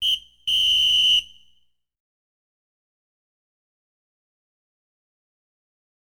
Звуки полицейского свистка
Судьиный свисток